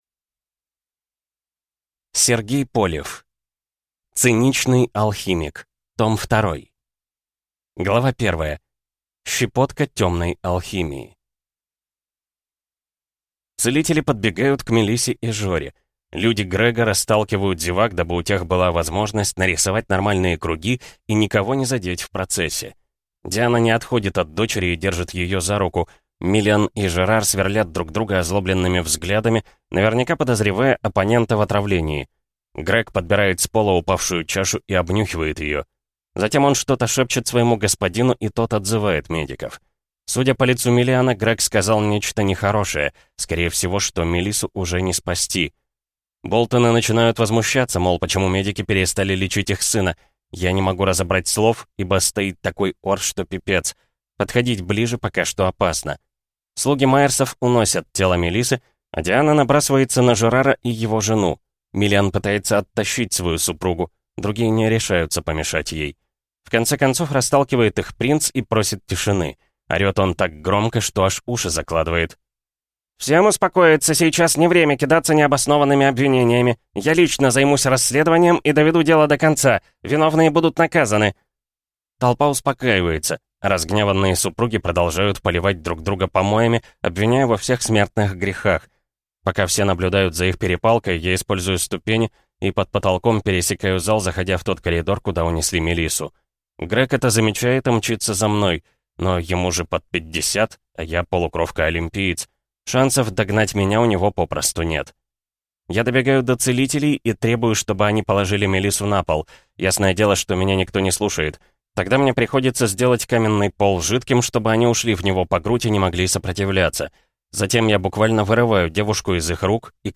Аудиокнига Циничный алхимик. Том 2 | Библиотека аудиокниг